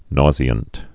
(nôzē-ənt, -zhē-, -sē-, -shē-)